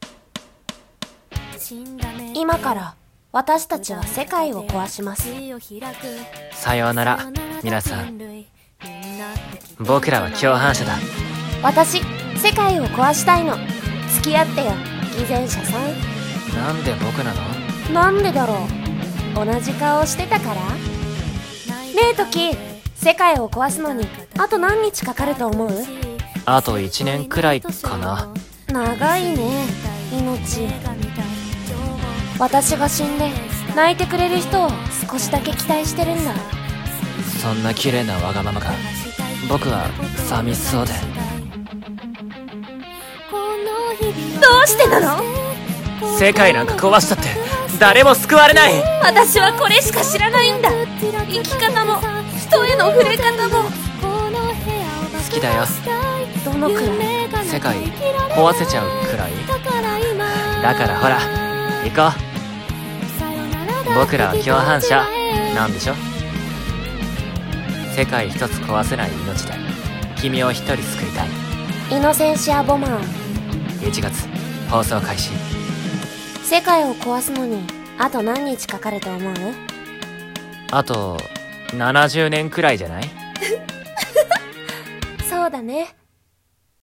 【アニメCM風声劇】イノセンシア ボマー